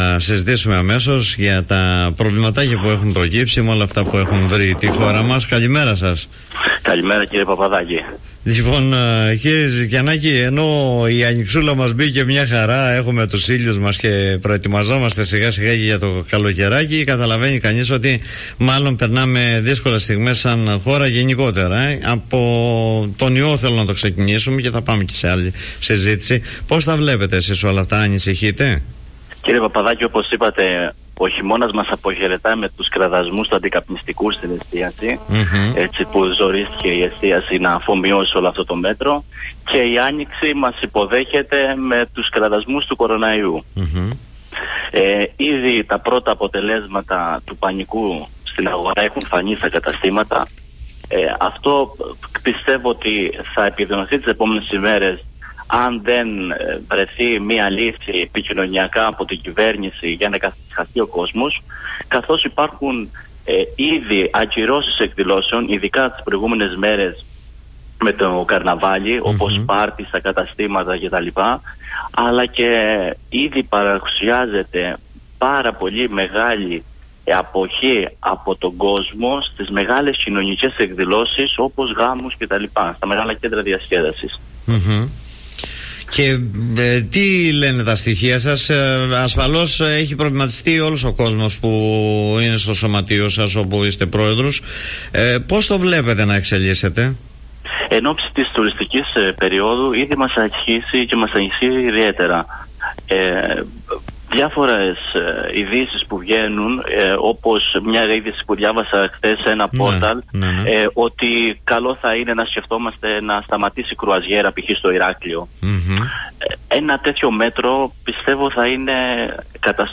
μίλησε στον Politica 89.8 και στην εκπομπή «Δημοσίως»